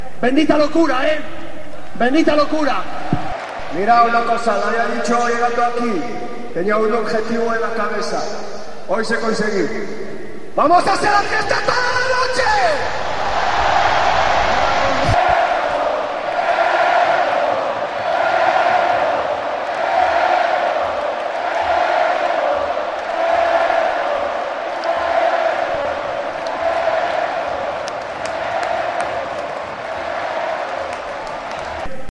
Sonidos del Ascenso del Deportivo